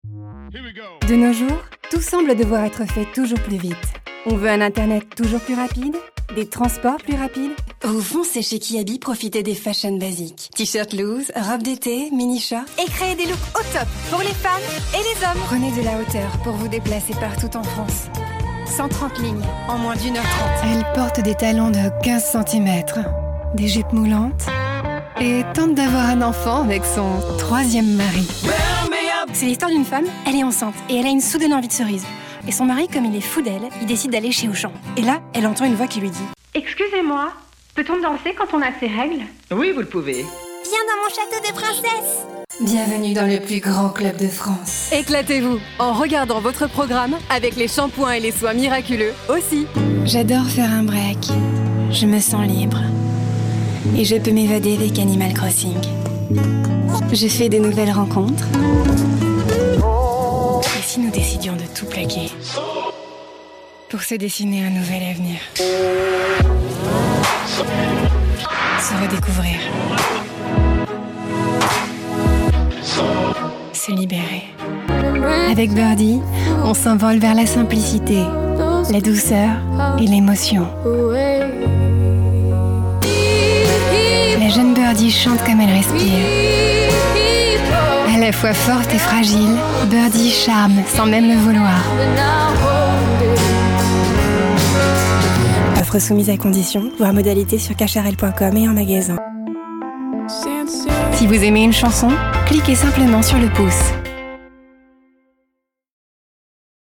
My voice is very versatile and can be lighter or deeper.
Native French voice actress and fluent English speaker.
Sprechprobe: Werbung (Muttersprache):